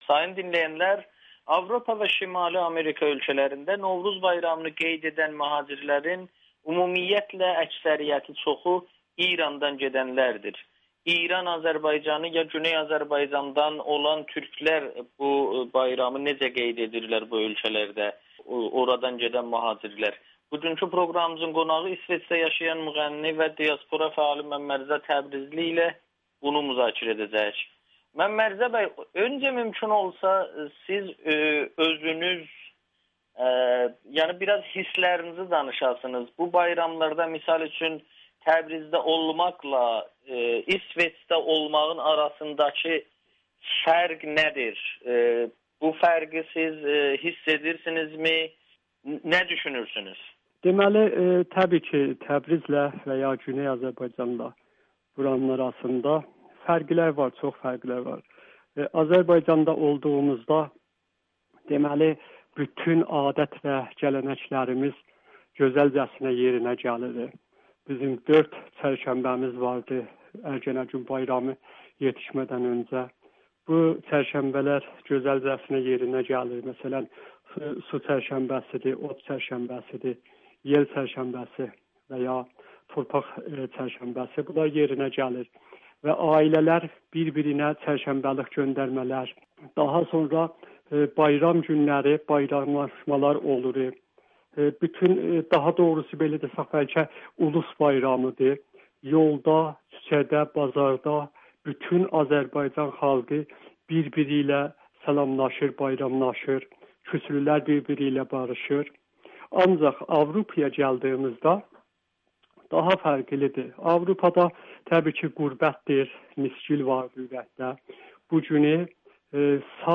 Avropada yaşayan güneyli mühacirlər bayramı necə qeyd edir? [Audio-Müsahibə]